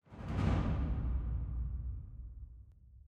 sfx_ui_combat_end.ogg